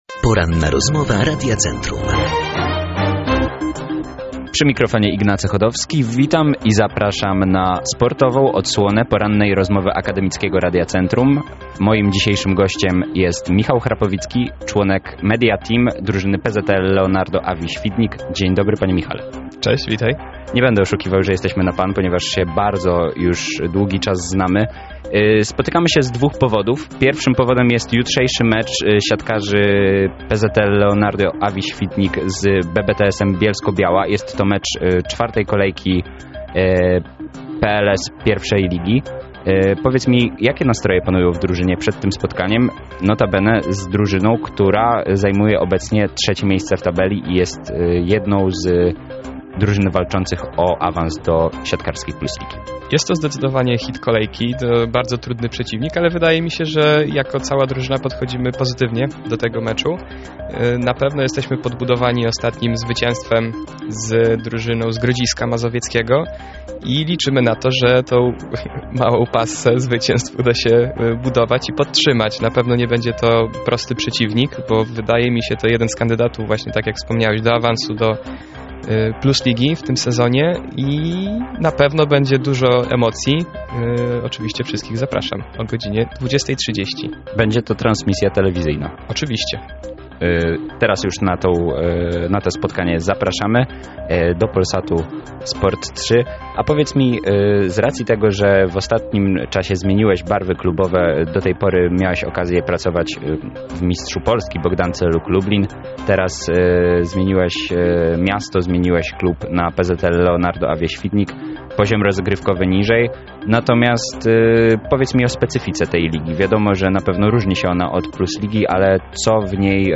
ROZMOWA.mp3